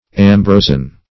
Ambrosian - definition of Ambrosian - synonyms, pronunciation, spelling from Free Dictionary
Ambrosian \Am*bro"sian\, a.